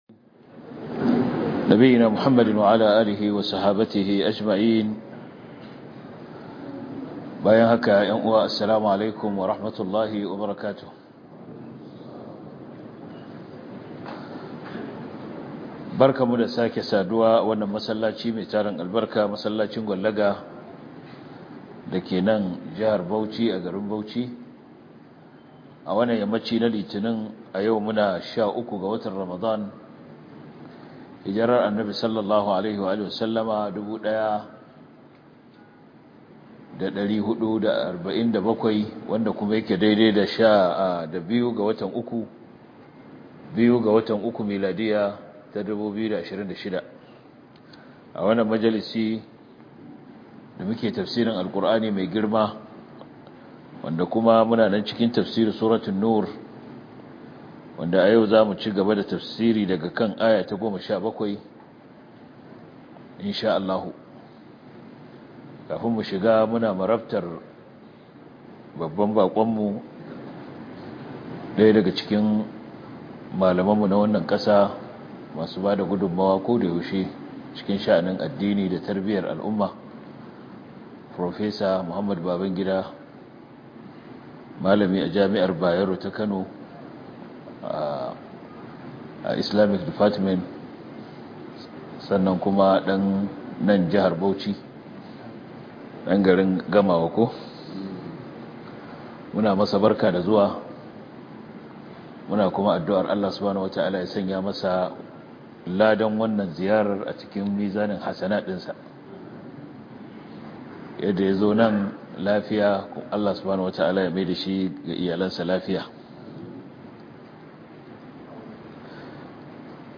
← Back to Audio Lectures 13 Ramadan Tafsir Copied!